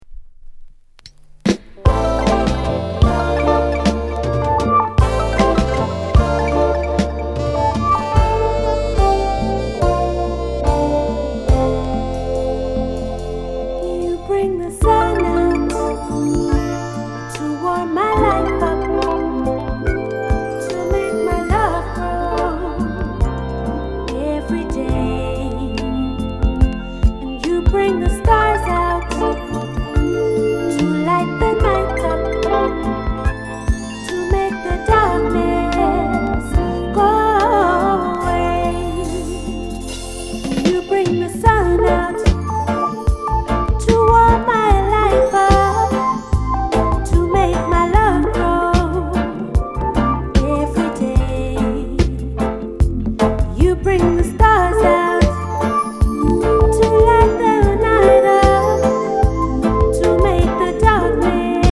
LOVERS ROCK